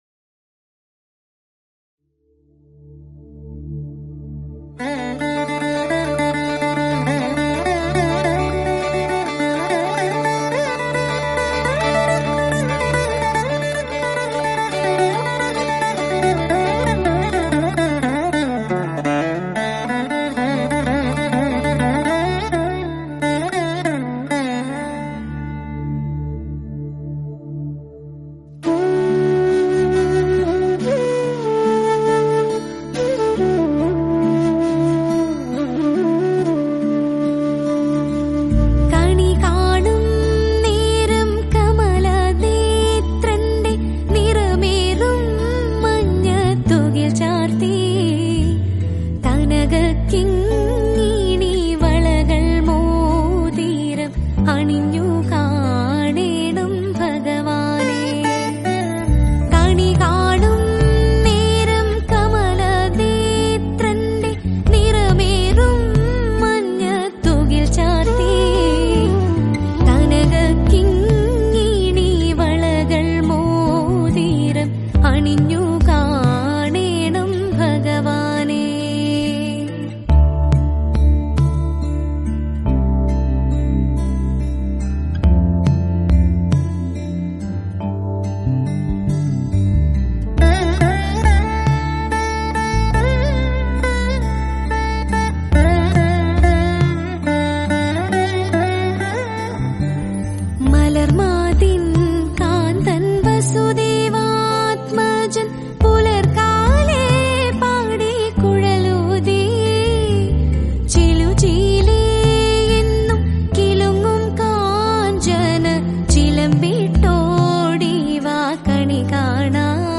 Releted Files Of Tamil Gana